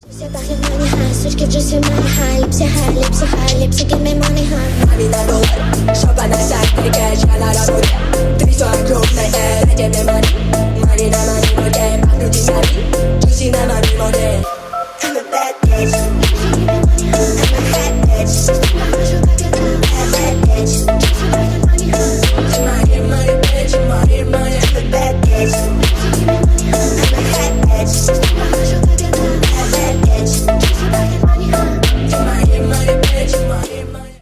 клубные # громкие